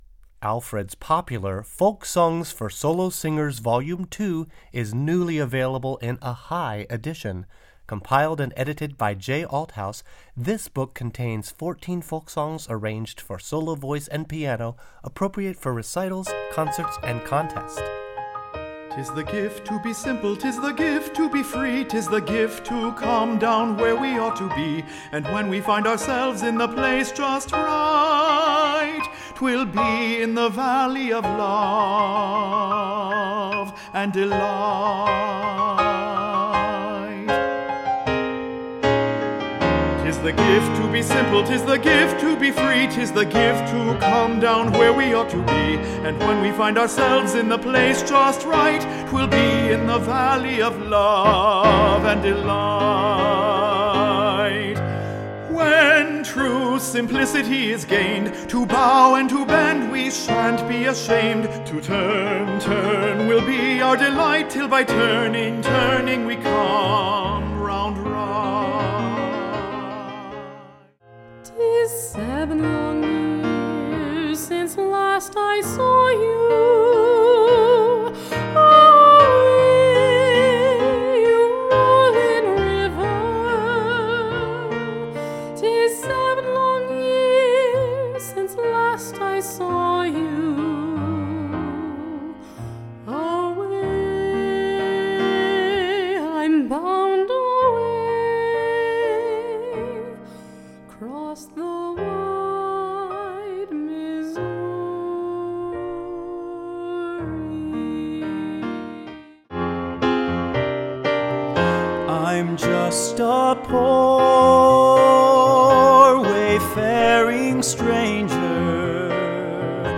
Voicing: High Voice